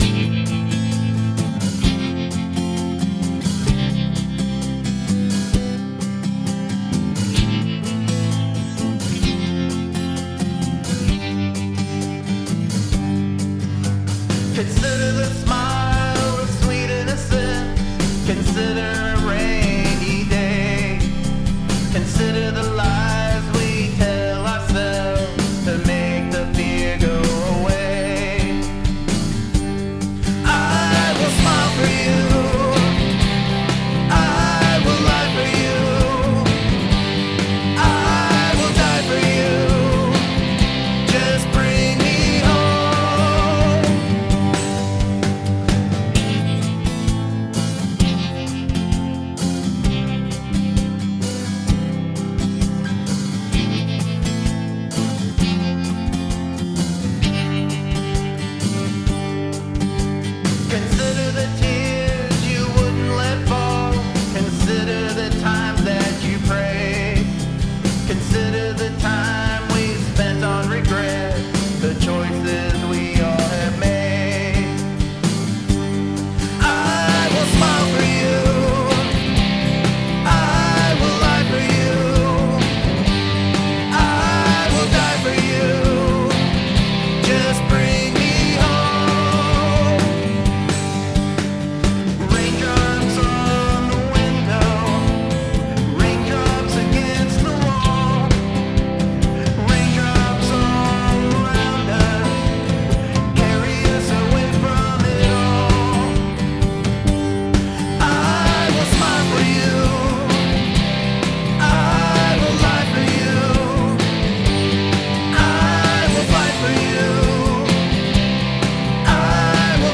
Acoustic guitar: Yairi six string acoustic
Electric guitar: Modern Player Fender Telecaster
Acoustic piano: Grand Piano samples
Synth: Union VSTi